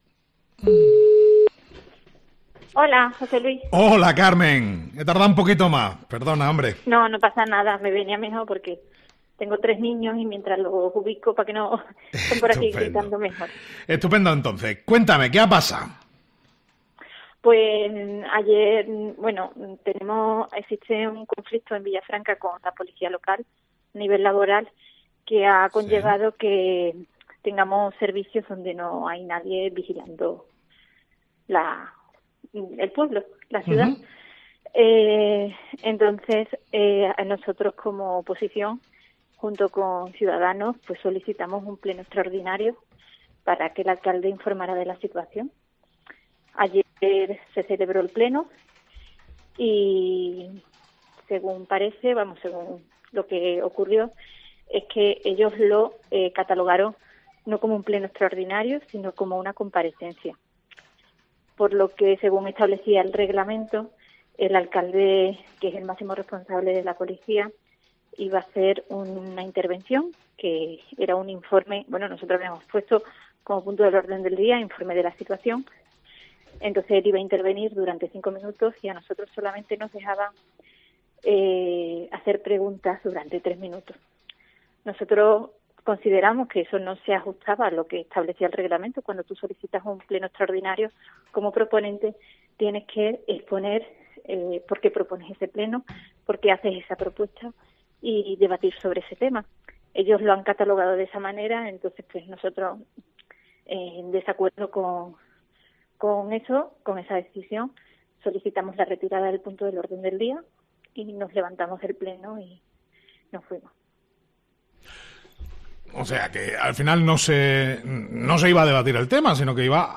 La convocatoria de un nuevo Pleno extraordinario por parte de la oposición quieren que sea una oportunidad crucial para que se aborden las preocupaciones y se discutan las posibles soluciones, como señala en el audio adjunto la portavoz del Partido Popular, Carmen Romero.